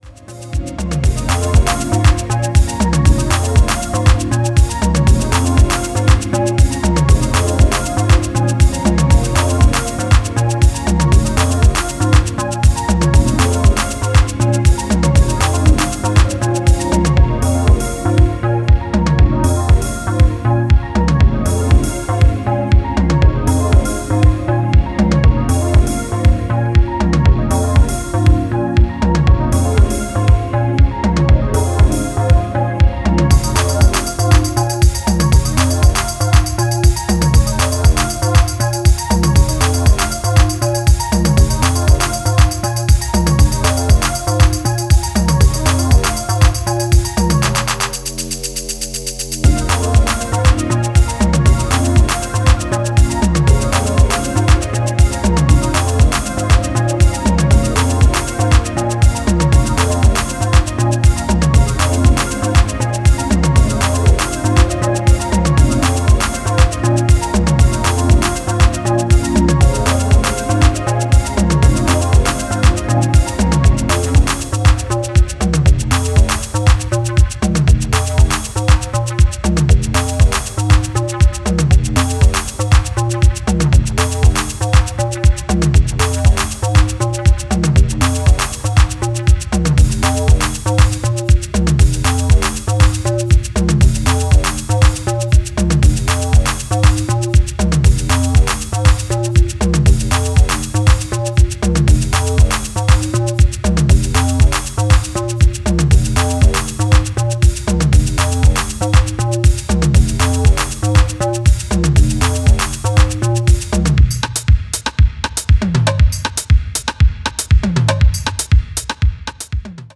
pure jacking acid house heat!